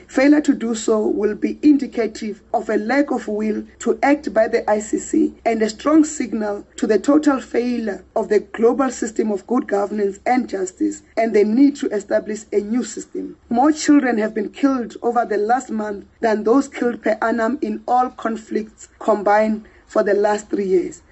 # Die minister in die Presidensie, Khumbudzo Ntshavheni, sê die Internasionale Strafhof se kantoor gaan voort om sy voete te sleep ondanks die erns van die situasie in Palestina sedert 2021. Ntshavheni het ‘n nuuskonferensie gehou oor die uitkomste van Donderdag se kabinetsvergadering.